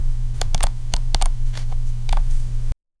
Free AI Sound Effect Generator
picking up a card
picking-up-a-card-vjbzihta.wav